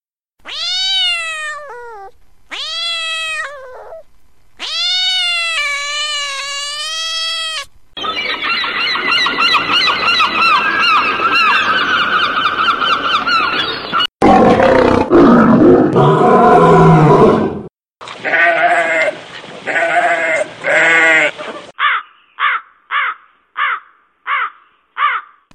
Вам нужно пройти маршрут по звукам животных.
кот
чайка
лев
баран
ворона